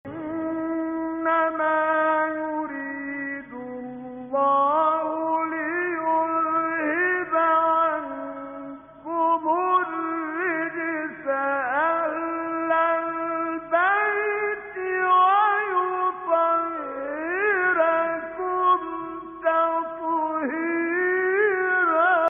به بهانه سالروز تخریب بقاع متبرکه بقیع کرسی تلاوت مجازی با محوریت آیه تطهیر را با صدای راغب مصطفی غلوش، کامل یوسف البهتیمی، شعبان عبدالعزیز صیاد و سیدمتولی عبدالعال از قاریان شهیر جهان اسلام می‌شنوید.
تلاوت آیه تطهیر با صوت کامل یوسف البهتیمی